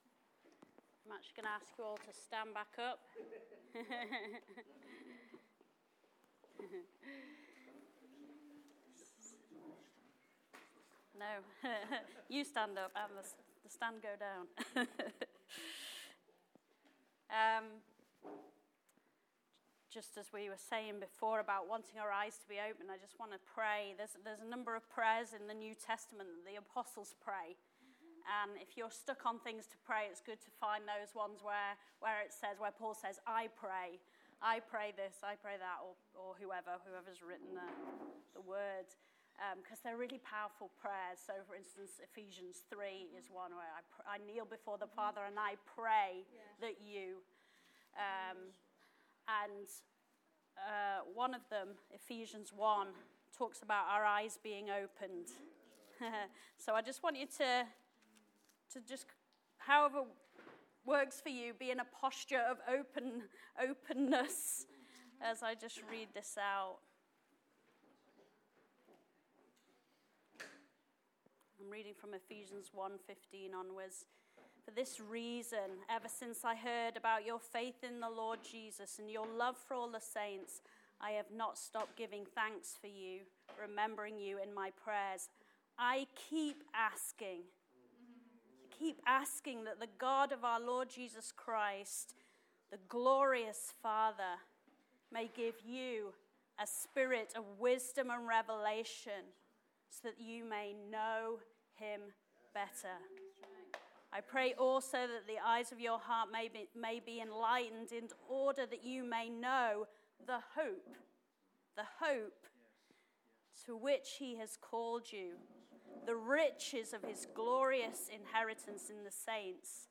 OCF Sermons: 2026-04-19